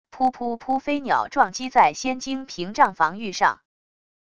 噗噗噗飞鸟撞击在仙晶屏障防御上wav音频